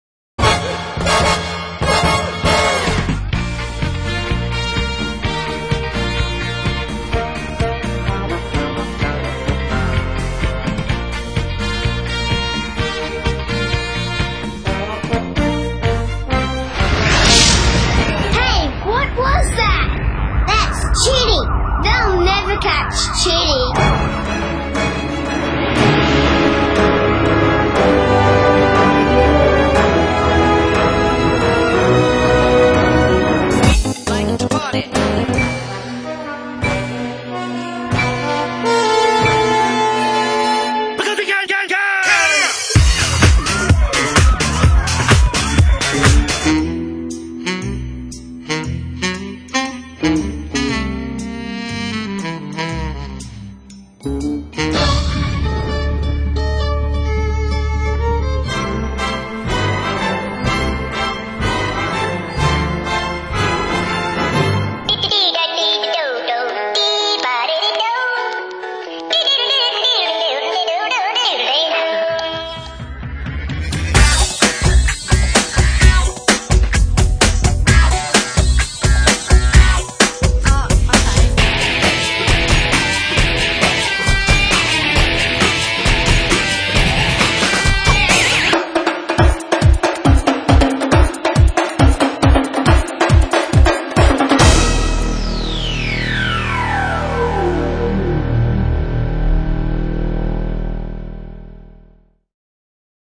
Change speed [without effecting the pitch]  original speed  faster speed